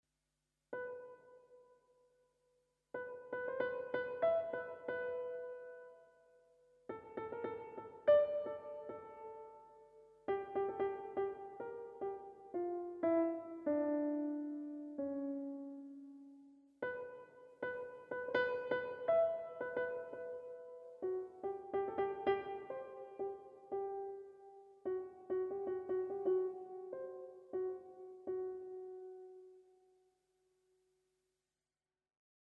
Einhundertfünfundzwanzig extrem kurze Sonaten für ein digitales Klavier
Sie sind komprimierte Miniaturen.